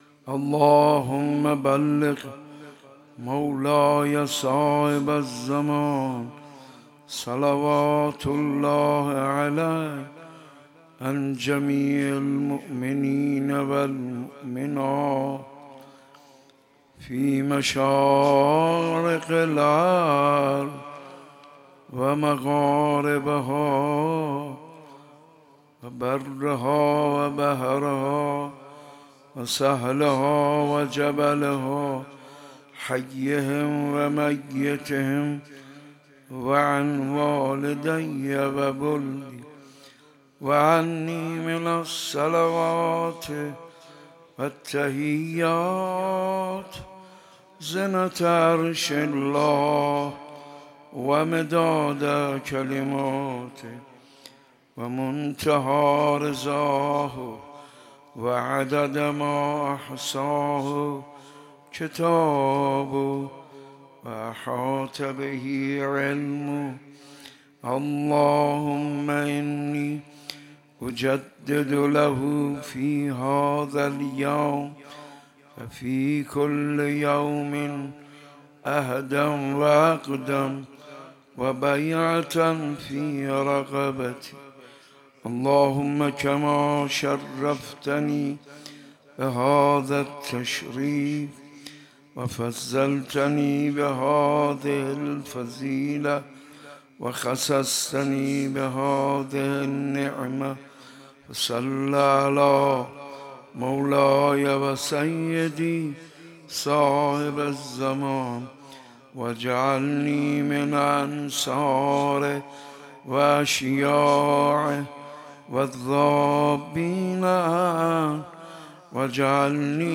مناجات با امام زمان عجل الله تعالی فرجه الشریف دهه اول محرم 1400 - روز چهارم